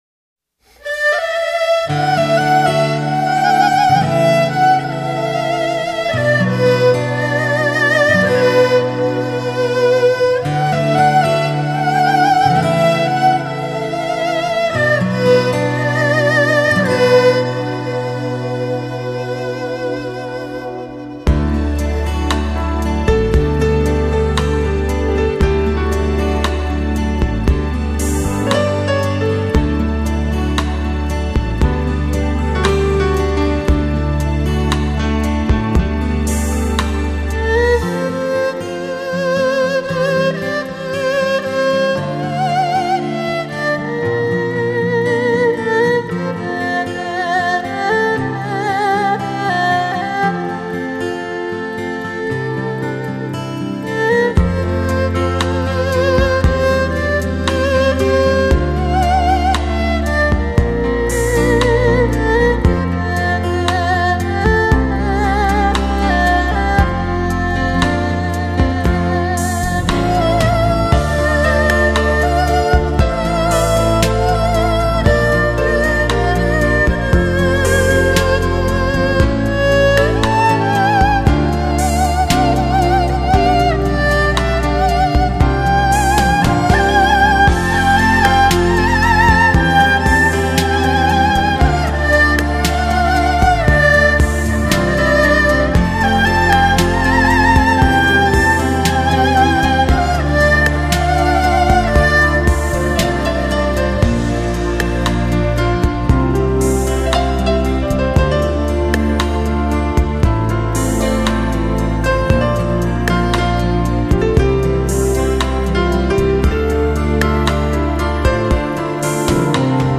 1958年4月生，二胡演奏家。